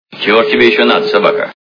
При прослушивании к/ф Иван Васильевич меняет профессию - Чего ж тебе еще надо собака качество понижено и присутствуют гудки.